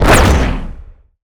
energy_blast_large_05.wav